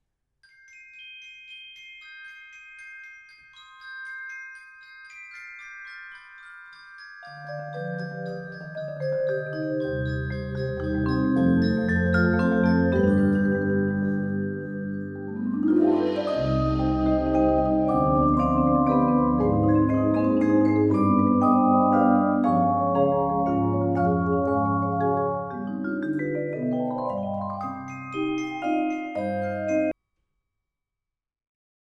« Percussions et cinéma »